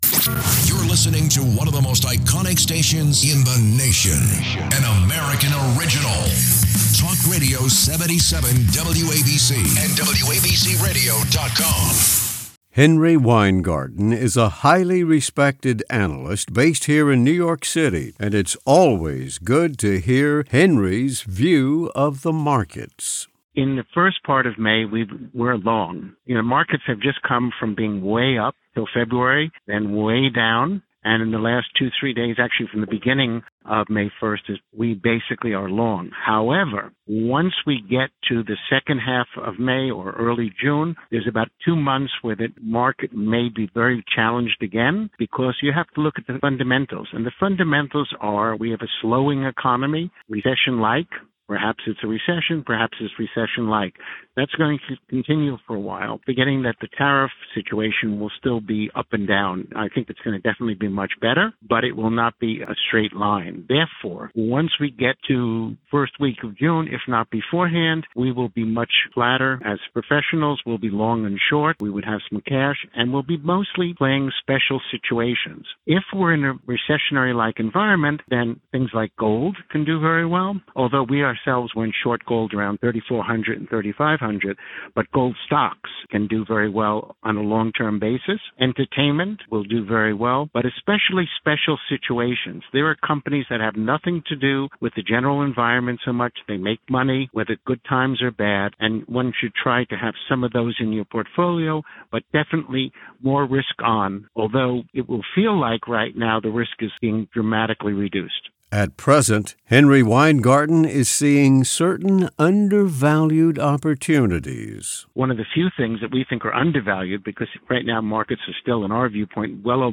June 8 2025 Markets Interview